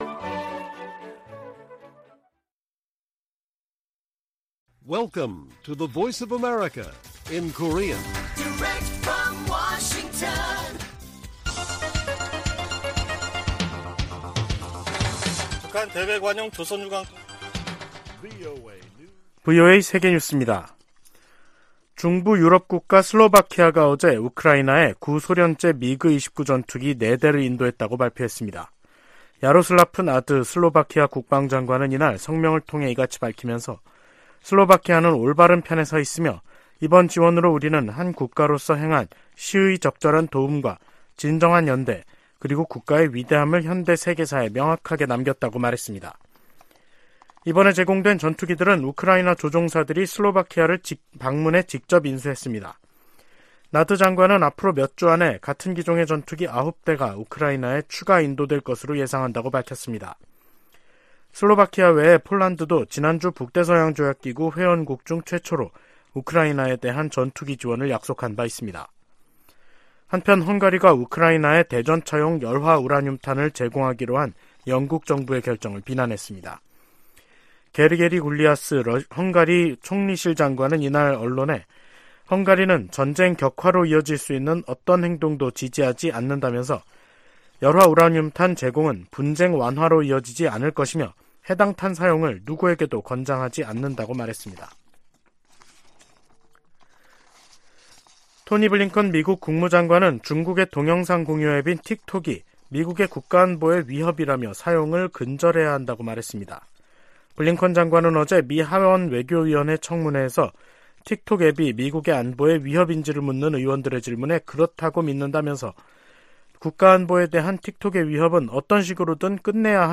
VOA 한국어 간판 뉴스 프로그램 '뉴스 투데이', 2023년 3월 24일 2부 방송입니다. 북한이 '핵 무인 수중 공격정' 수중 폭발시험을 진행했다고 대외관영 매체들이 보도했습니다. 로이드 오스틴 미 국방장관은 북한을 지속적인 위협으로 규정하며 인도태평양 지역에서 방위태세를 강화하고 훈련 범위와 규모도 확대하고 있다고 밝혔습니다. 윤석열 한국 대통령은 '서해 수호의 날' 기념사에서 북한의 무모한 도발에는 대가를 치르게 하겠다고 강조했습니다.